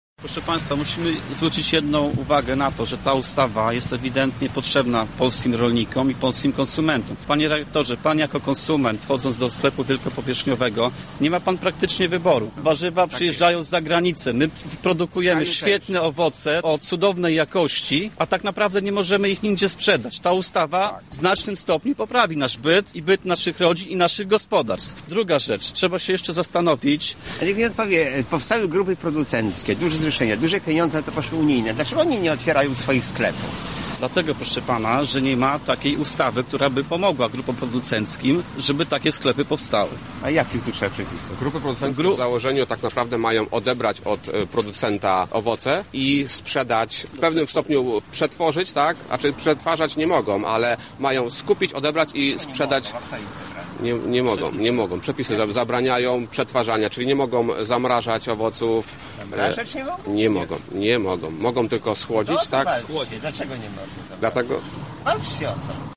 Podczas spotkania doszło do burzliwej dyskusji. Tematem była chociażby kwestia zakładania sklepów przez rolników.
dyskusja